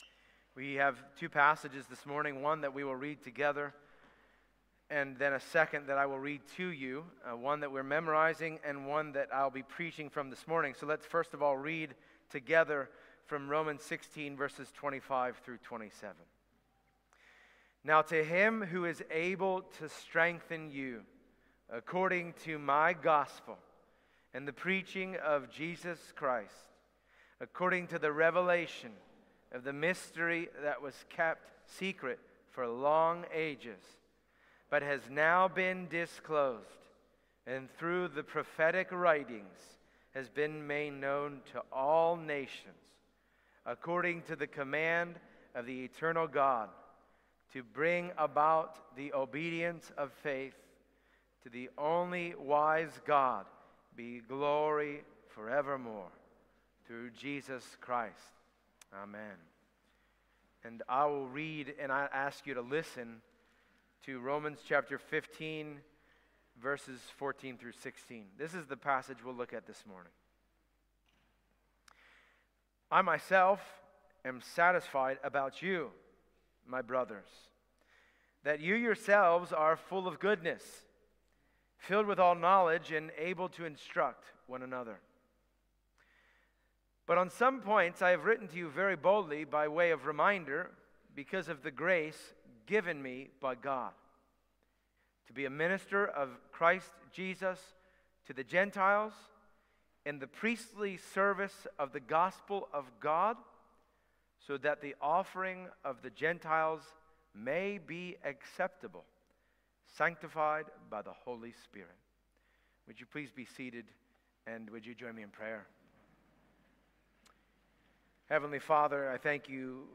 Passage: Romans 15:14-16 Service Type: Sunday Morning Download Files Bulletin « Welcome One Another Providence